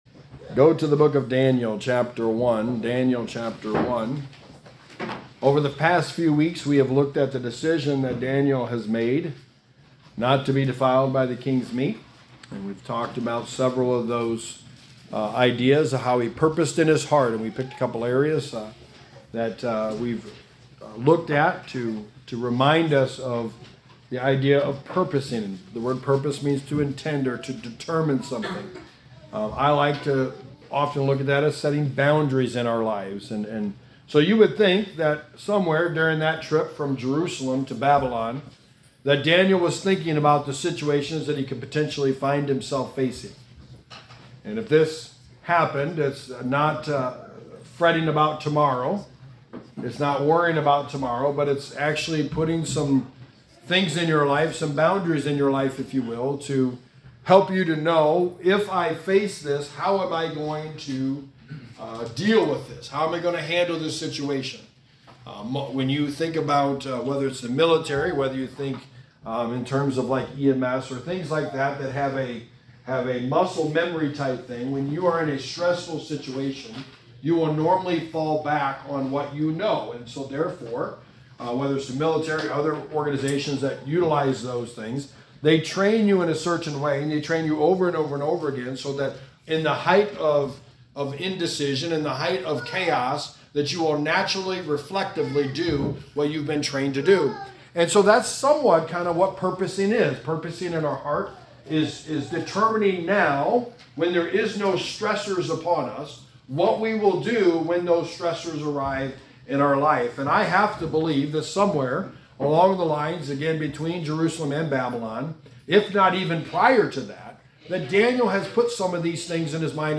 Walking With Daniel – A study of the life of Daniel / Sermon #7: Choosing Who To Walk With
Service Type: Sunday Morning